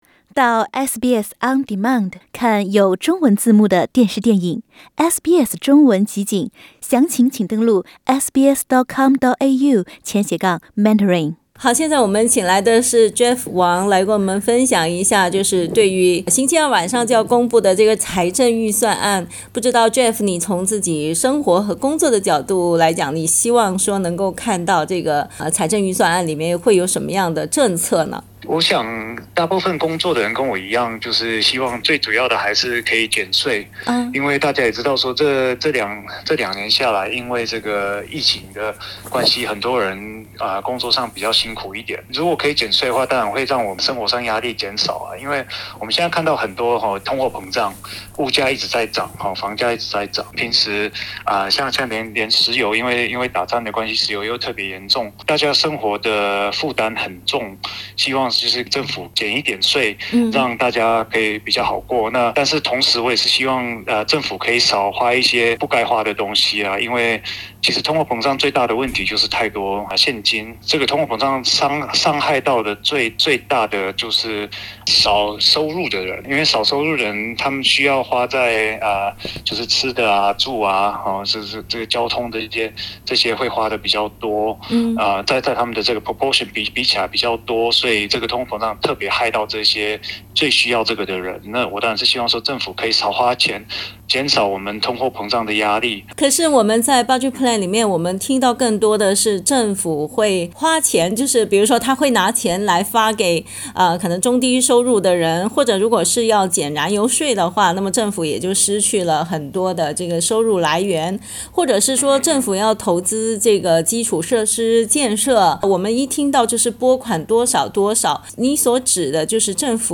SBS普通话采访了几位华人民众，倾听他们对于今晚要公布的预算案的期待和看法。